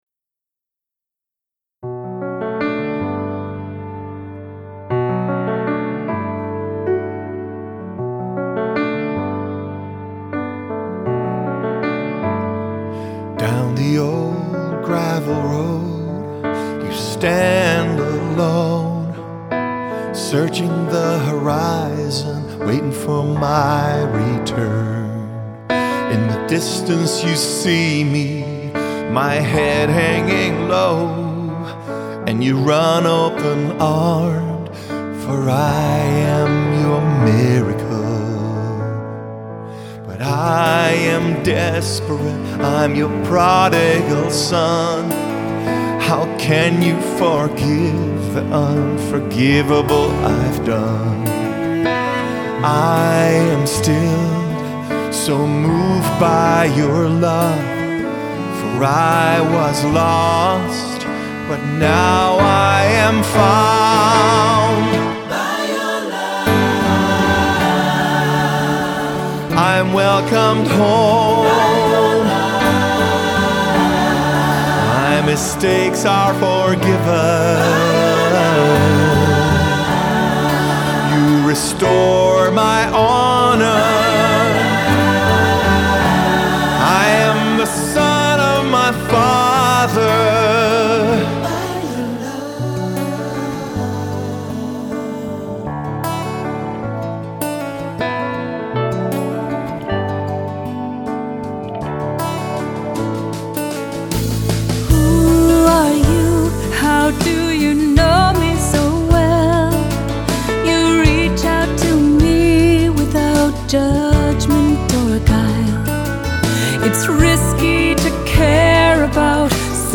Accompaniment:      Keyboard
Music Category:      Christian
For cantor or soloist.